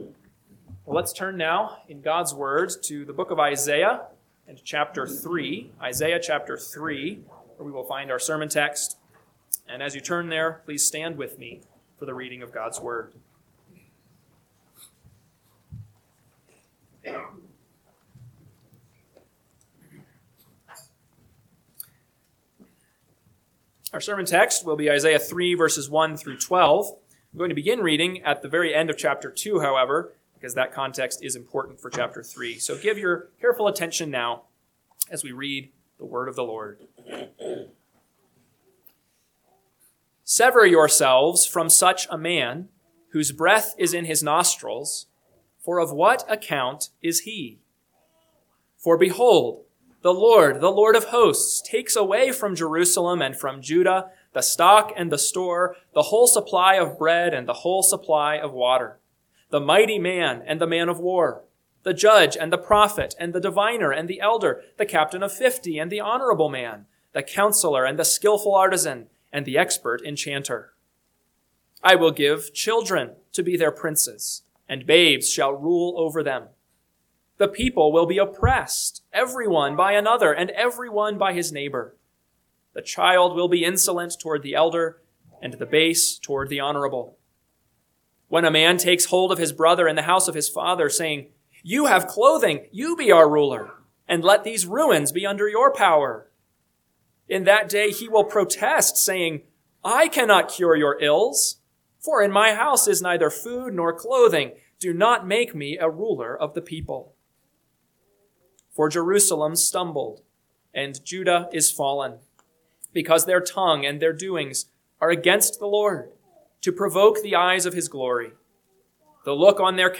AM Sermon – 11/2/2025 – Isaiah 3:1-12 – Northwoods Sermons